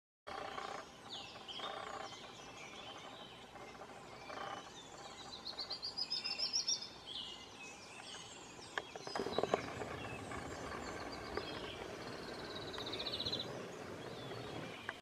Pale-legged Warbler (Myiothlypis signata)
Life Stage: Adult
Location or protected area: Parque Nacional Calilegua
Condition: Wild
Certainty: Recorded vocal